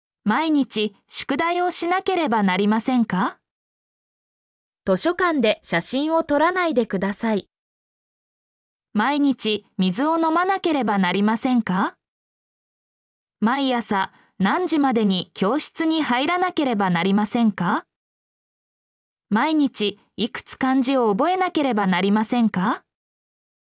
mp3-output-ttsfreedotcom-37_DaxjAjt2.mp3